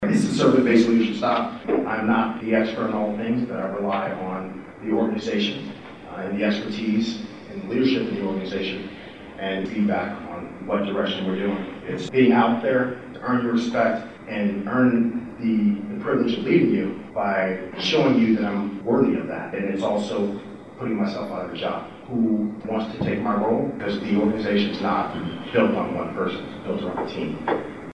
The Riley County Law Board held a three hour public forum at the Manhattan Fire Department Headquarters.